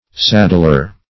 Saddler \Sad"dler\, n.